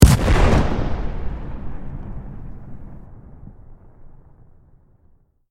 medium-explosion-8.ogg